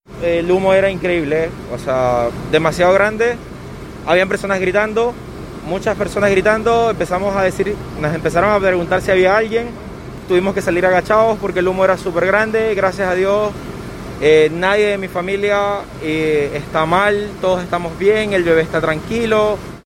El combate del fuego se vio complicado por la cantidad de humo que generaron las llamas. Así lo relató un vecino.